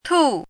“吐”读音
吐字注音：ㄊㄨˇ/ㄊㄨˋ
国际音标：tʰu˨˩˦;/tʰu˥˧
tù.mp3